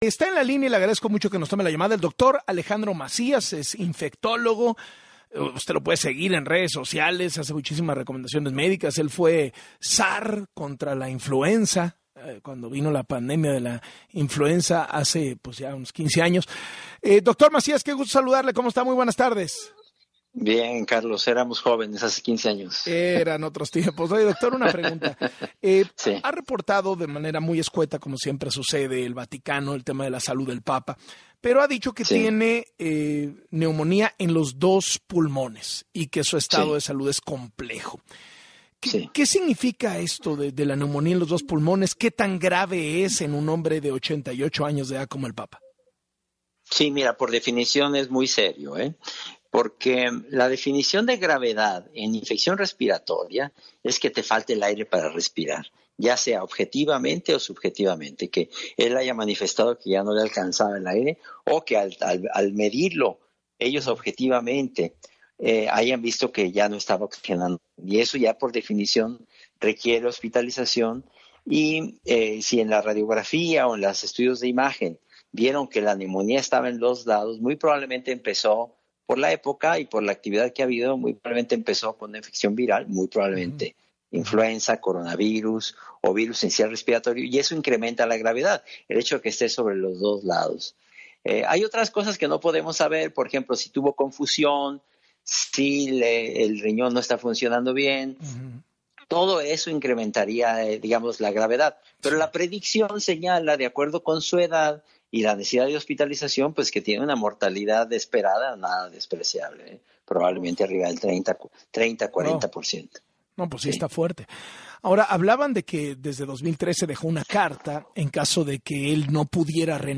En entrevista con Carlos Loret de Mola